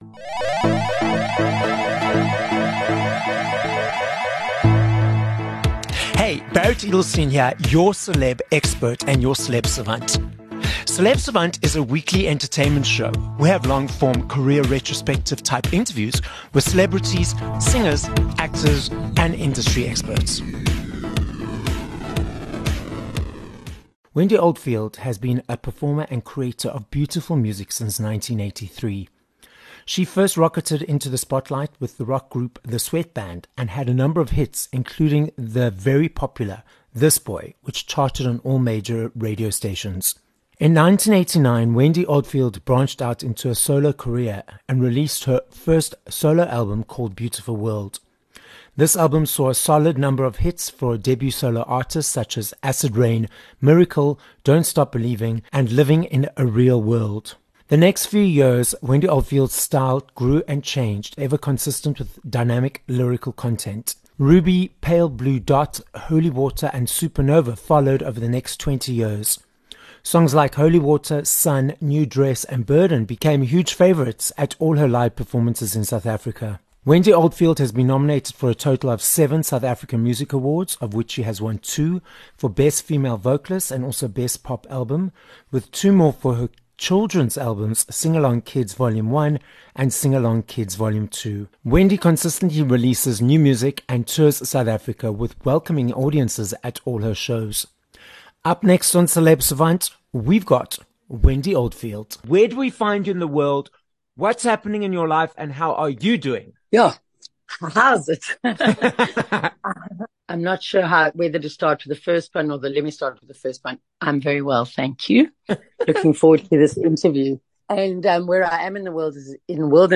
19 Jun Interview with Wendy Oldfield